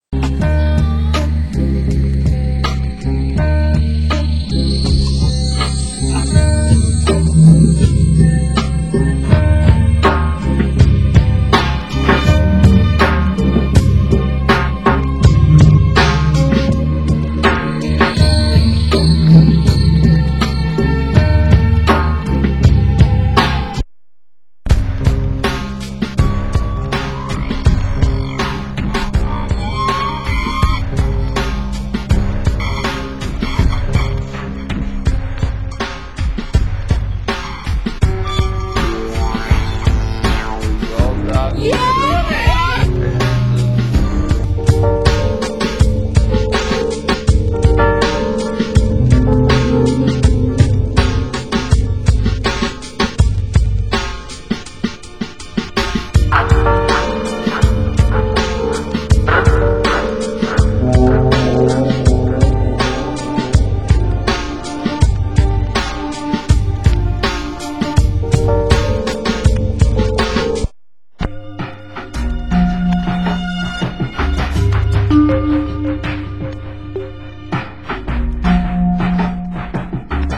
Genre: Leftfield